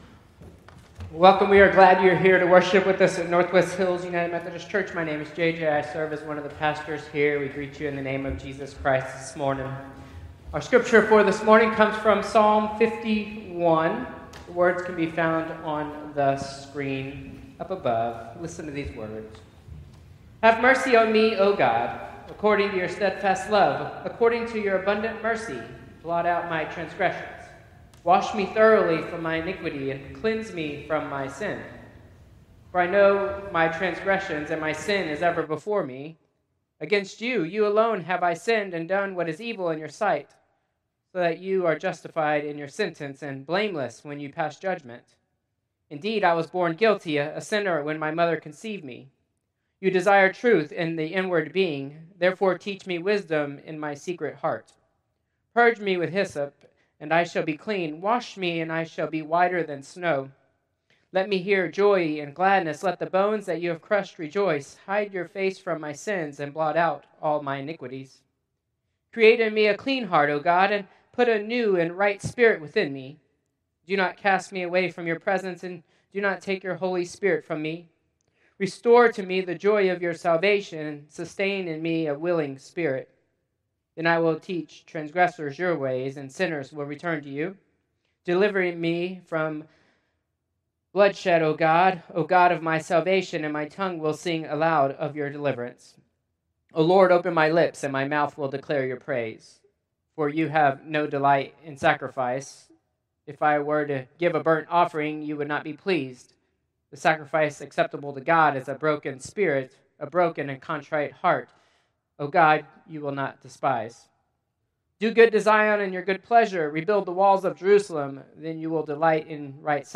Traditional Service 9/21/2025